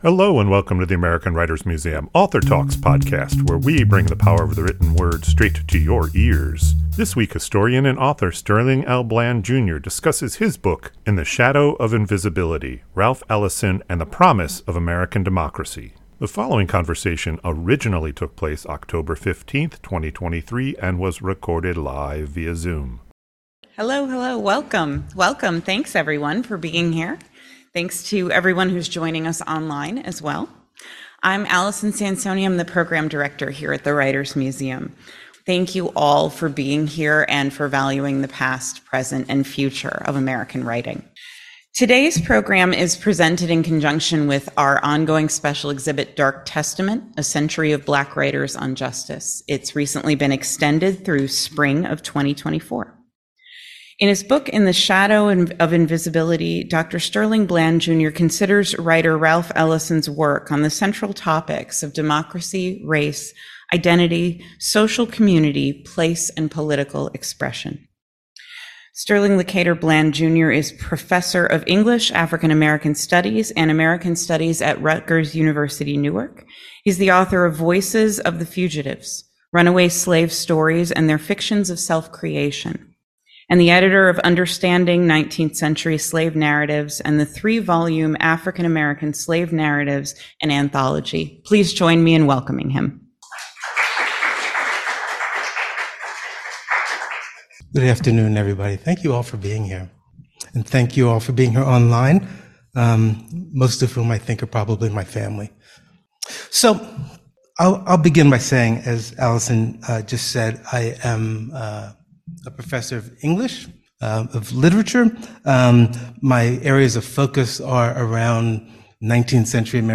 This conversation originally took place October 15, 2023 and was recorded live at the American Writers Museum.